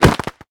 Minecraft Version Minecraft Version latest Latest Release | Latest Snapshot latest / assets / minecraft / sounds / entity / player / attack / crit3.ogg Compare With Compare With Latest Release | Latest Snapshot